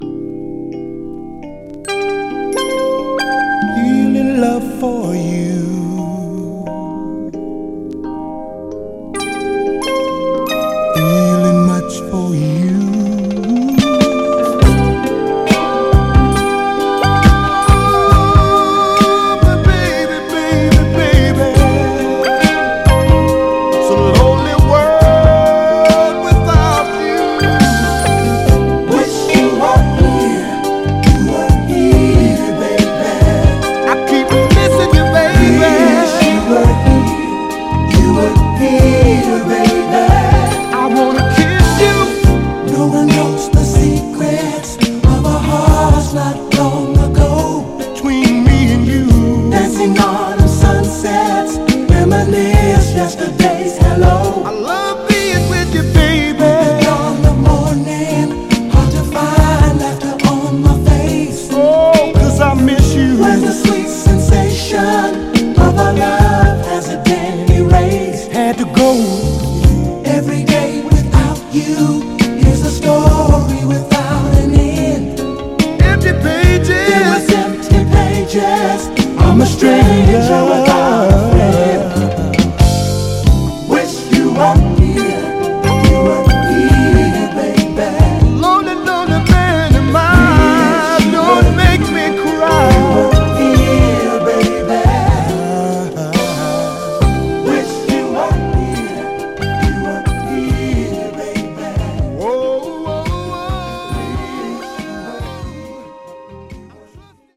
モノ/ステレオ収録のホワイト・プロモ盤。
※試聴音源は実際にお送りする商品から録音したものです※